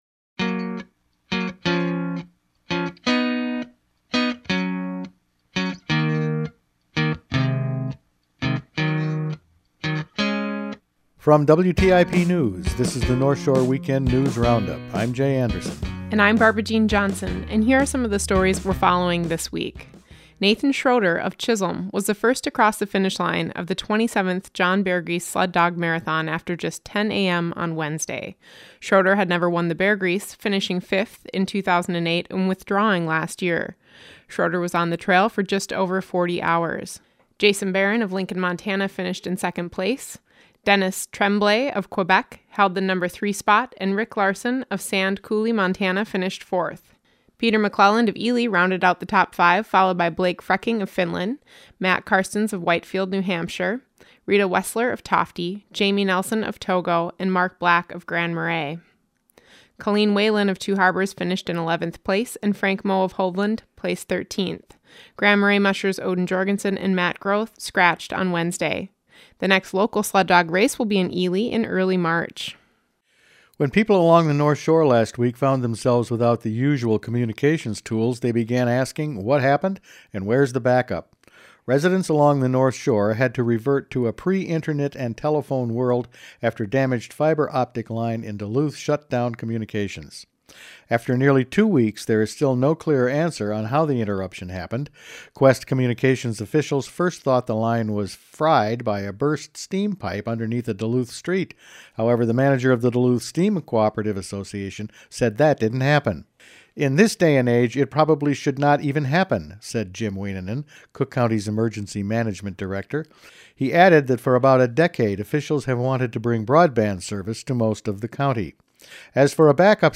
Each week the WTIP News Department provides a summary of the stories it has been following that week.